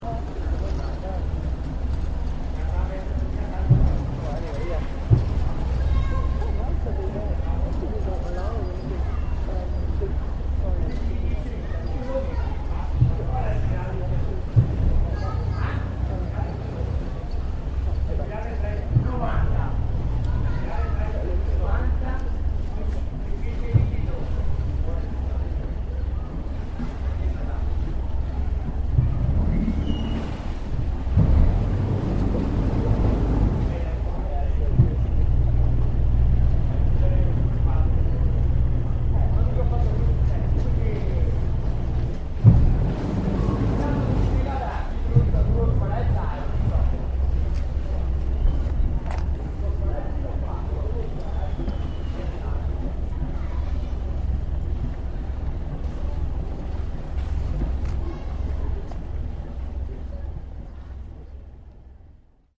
Boat turning around, Venice, September 2013.